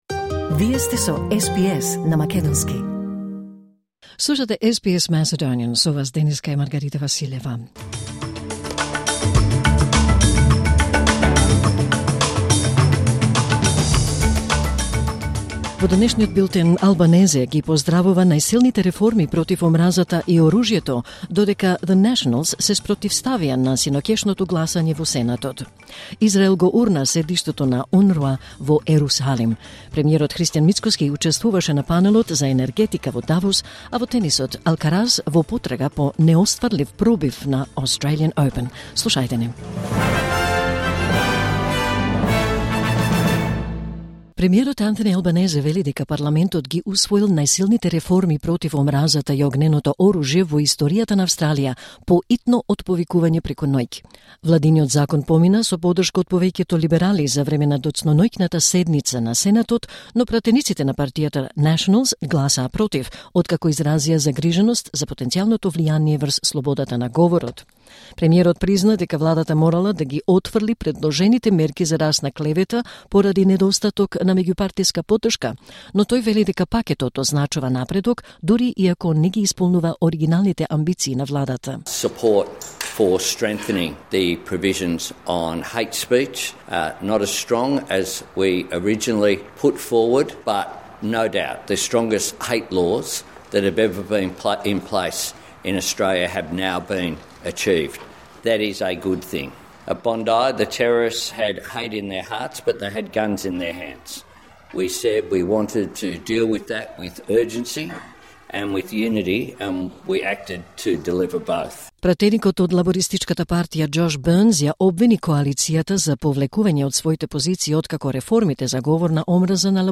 Вести на СБС на македонски 21 јануари 2026